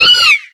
Cri de Skitty dans Pokémon X et Y.